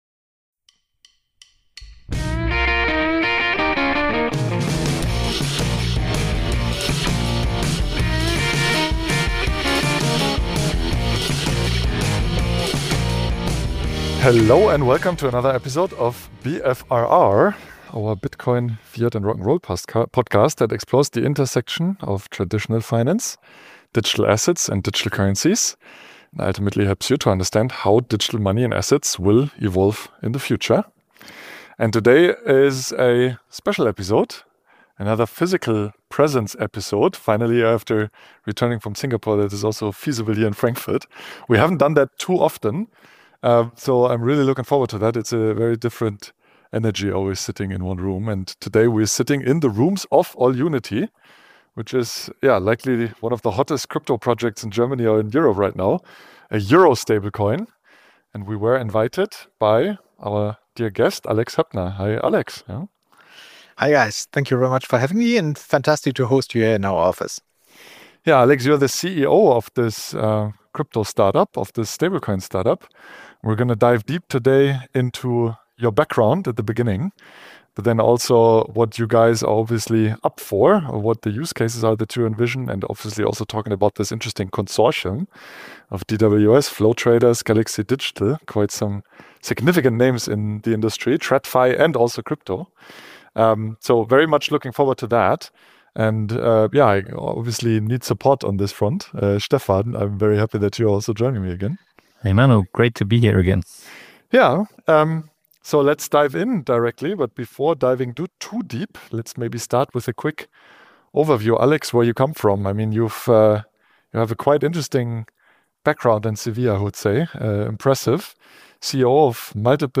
Recorded in Frankfurt at AllUnity’s offices, this in-person conversation dives deep into the intersection of traditional finance, digital assets, and the future of European money on-chain.